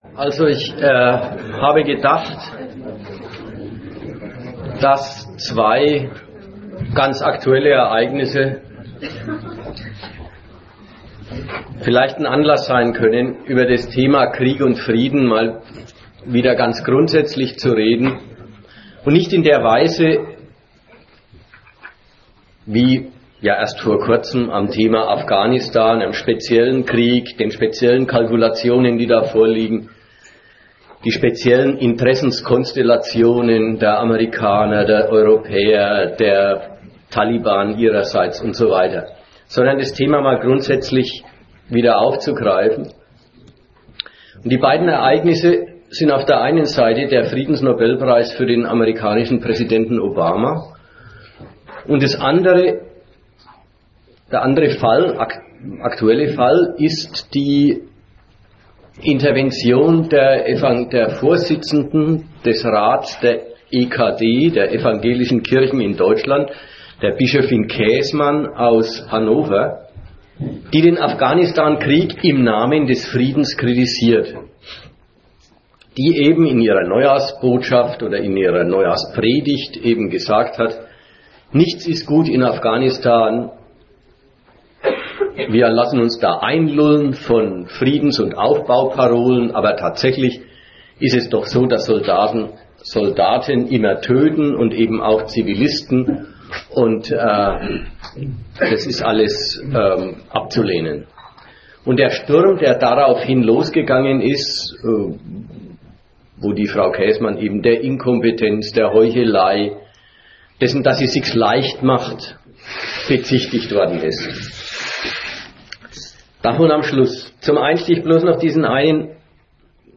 Auf dem Vortrag wird im Detail ausgeführt, wie die beiden Alternativen der Außenpolitik zusammengehören.